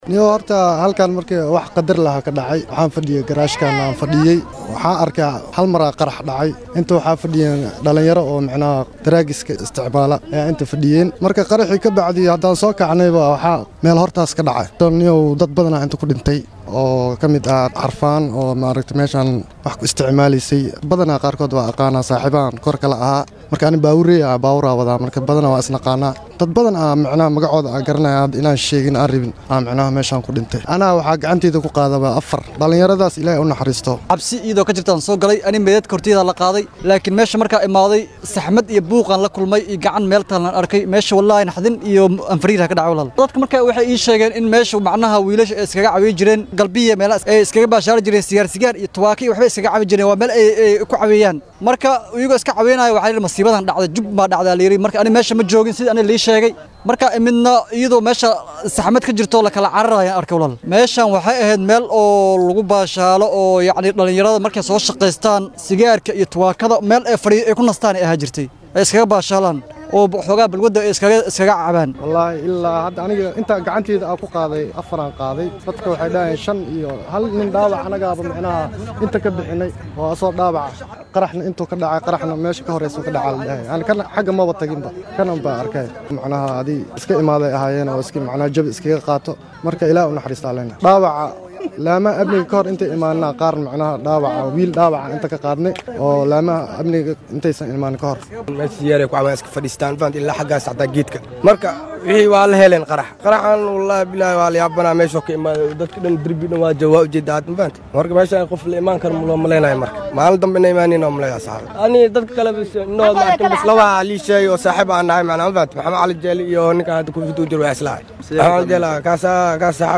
Goobjoogayaal ayaa warbaahinta ugu warramay sida ay wax u dhaceen.
Goojoogayaal-qarax-Muqdisho.mp3